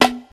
snarehorse.wav